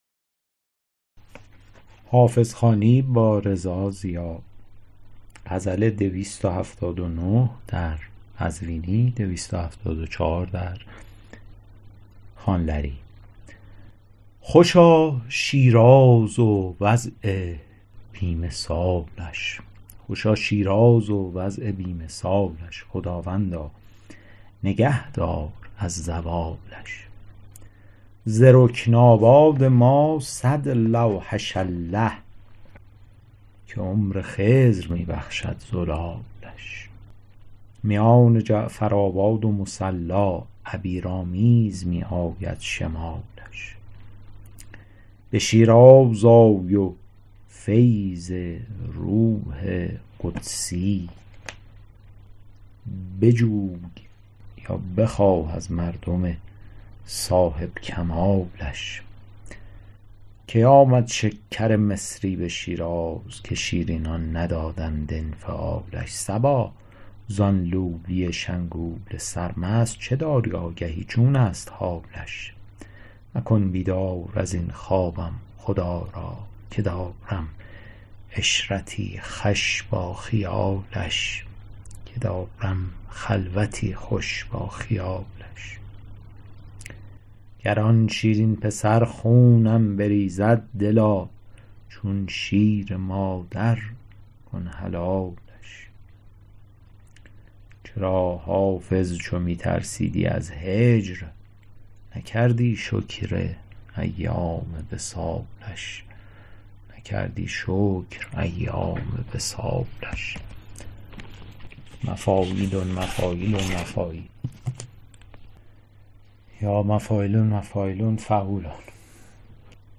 شرح صوتی غزل شمارهٔ ۲۷۹